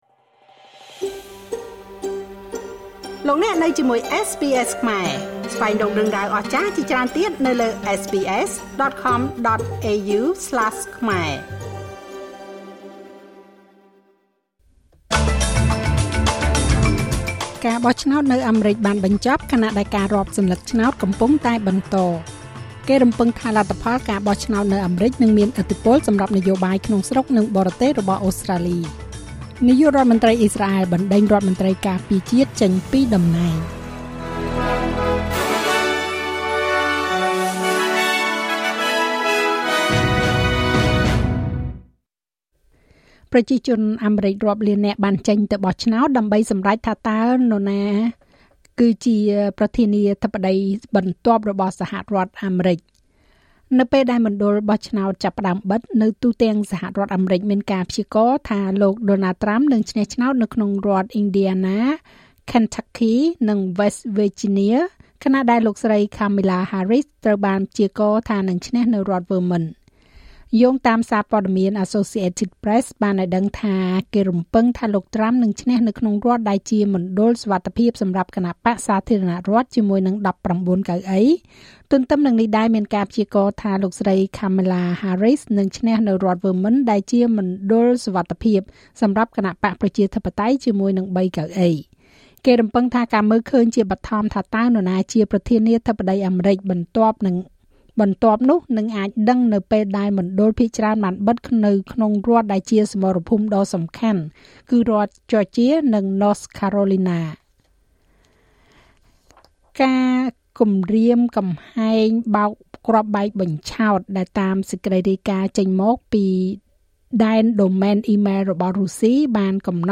នាទីព័ត៌មានរបស់SBSខ្មែរ សម្រាប់ ថ្ងៃពុធ ទី៦ ខែវិច្ឆិកា ឆ្នាំ២០២៤